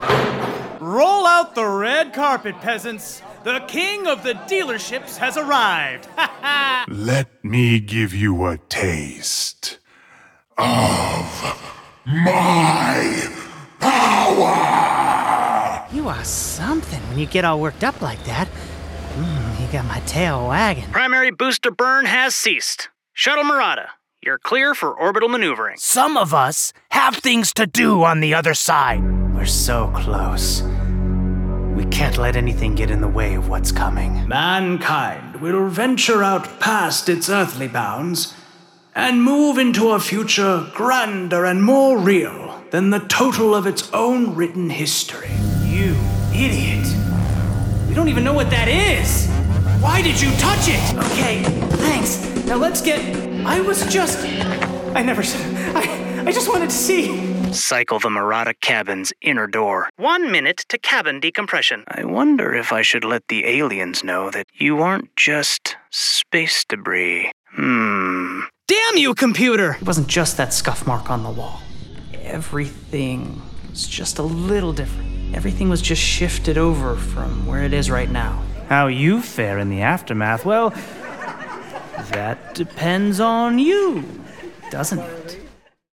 Character, Cartoon and Animation Voice Overs
Adult (30-50) | Yng Adult (18-29)